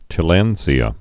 (tĭ-lăndzē-ə)